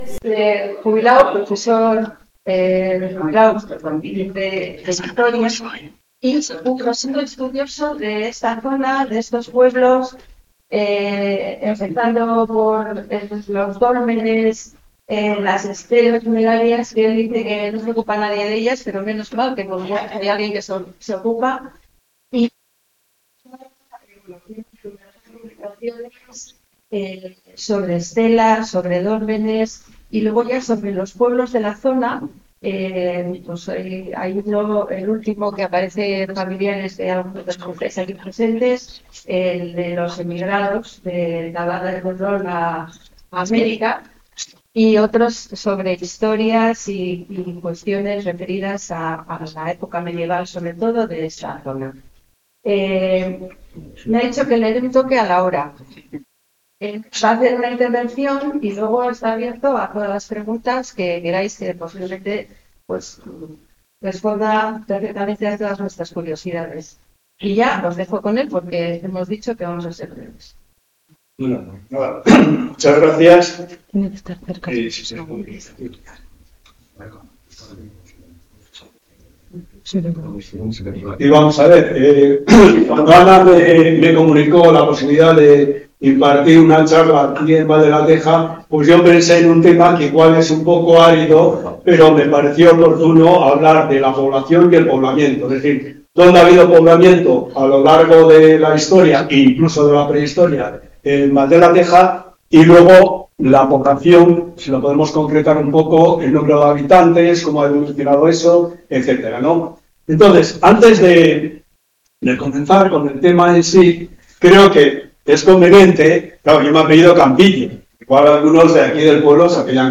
Charla sobre el poblamiento y población a lo largo de la historia de Valdelateja.
charla+valdelateja.mp3